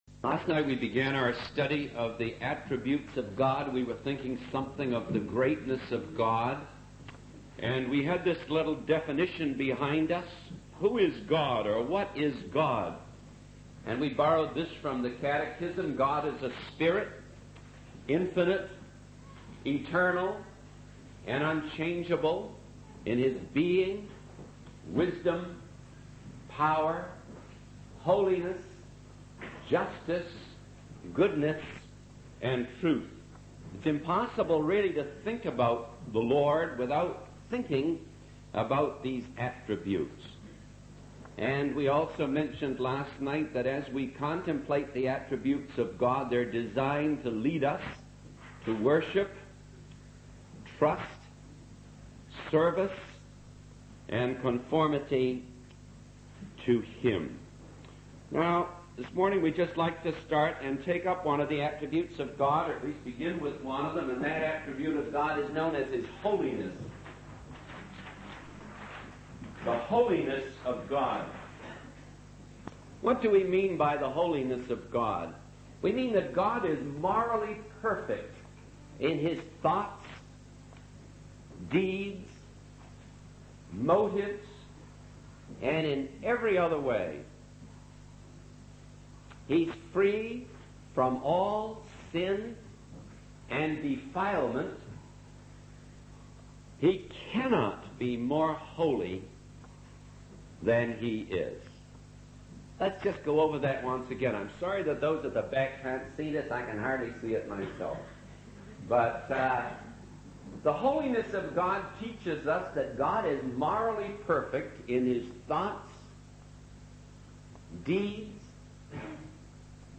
In this sermon, the preacher discusses the concept of beauty and how it is often used to judge people's worth. He emphasizes that Jesus, despite not having physical beauty, came into the world to show that true worth is not determined by appearance.